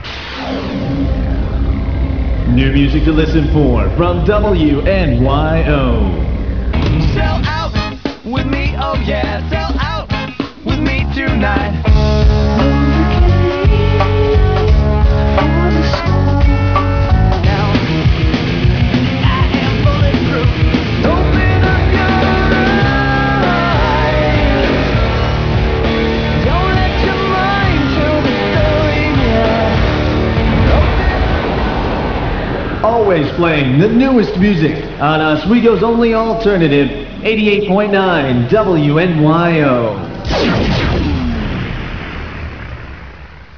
40 second New Music Promo for WNYO -FM (downloads in about five minutes at 28.8kbs)